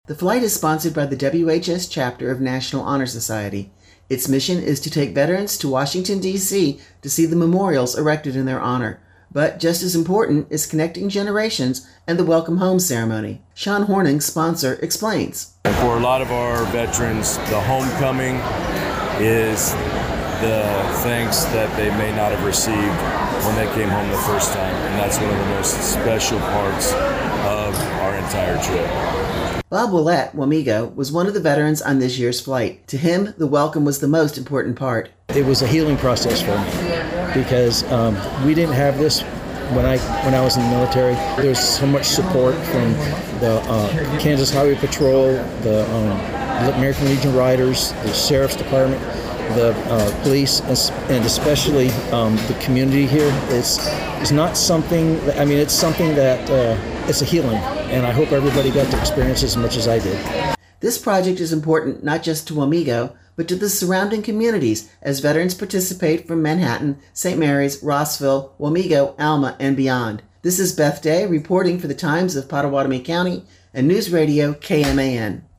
Veterans returned home on Friday to a larger than life welcome home after the return of the Wamego High School Honor Flight. KMAN contributing reporter